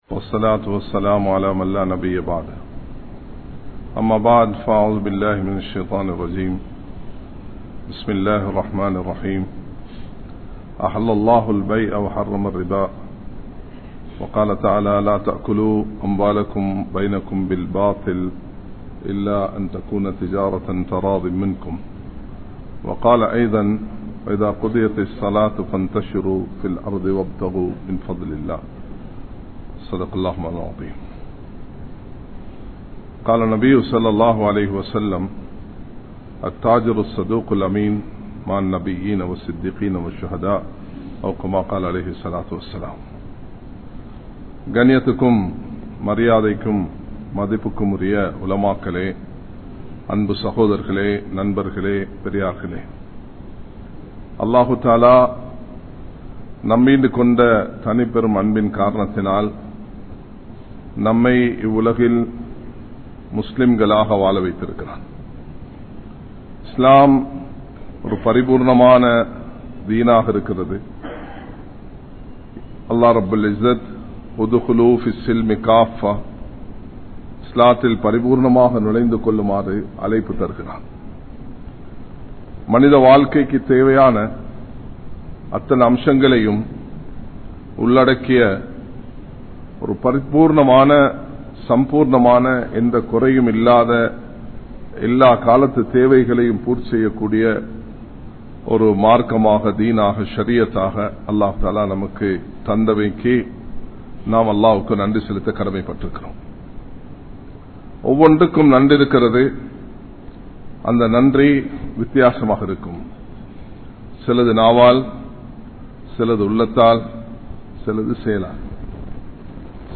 Tholi Nutpa Valarchium Islamiya Valihaattalum (தொழிநுட்ப வளர்ச்சியும் இஸ்லாமிய வழிகாட்டலும்) | Audio Bayans | All Ceylon Muslim Youth Community | Addalaichenai
Makkah Jumua Masjidh